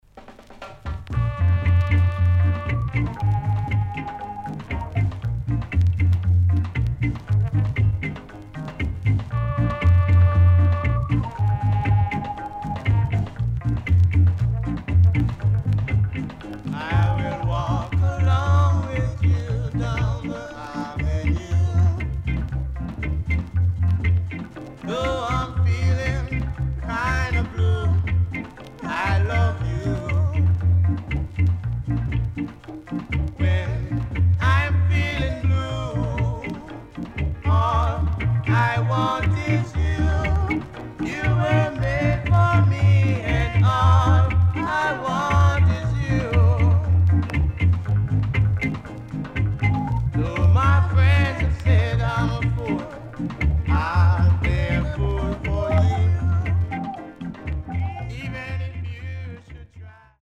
CONDITION SIDE A:VG(OK)
SIDE A:所々チリノイズがあり、少しプチノイズ入ります。